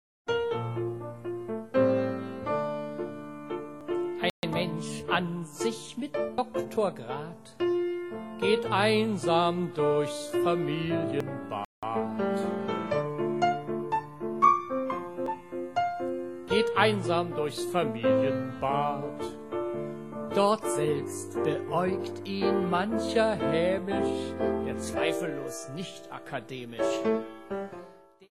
Heiteres Soloprogramm am Klavier